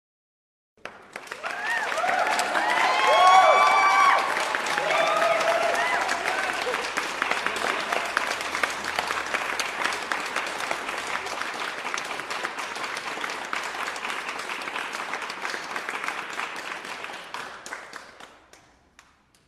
Audience Cheering And Clapping.mp3